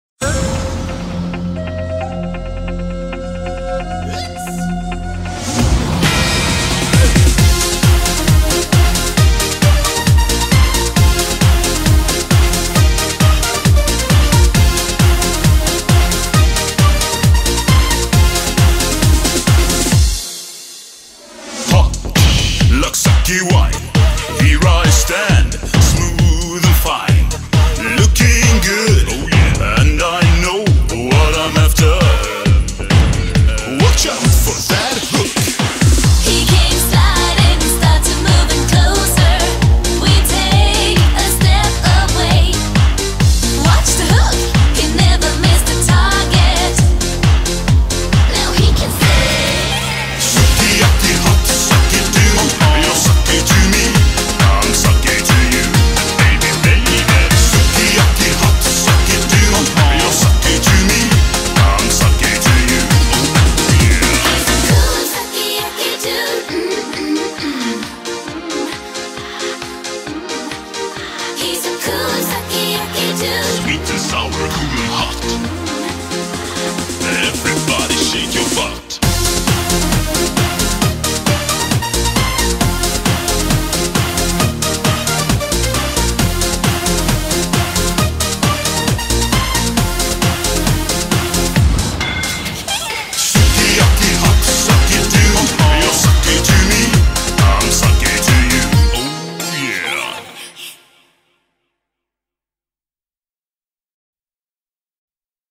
BPM134
Audio QualityMusic Cut